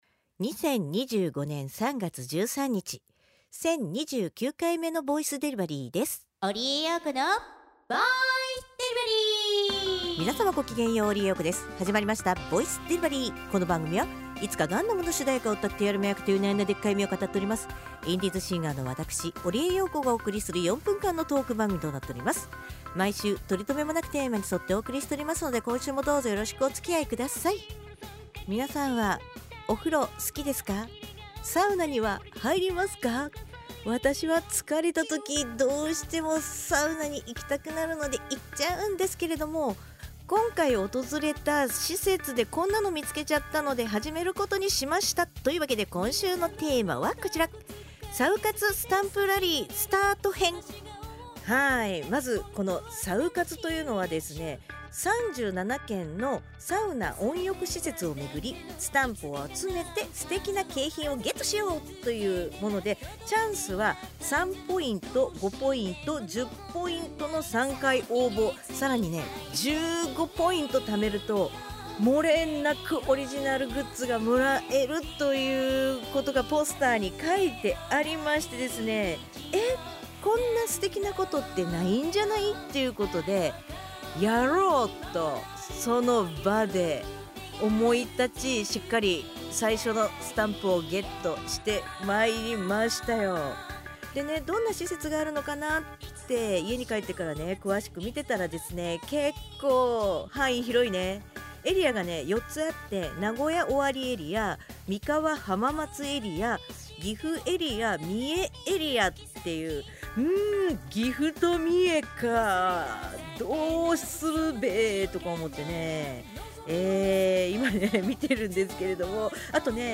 毎週水曜日か木曜日更新の４分間のトーク番組（通称：ぼいでり）時々日記とTwitterアーカイブ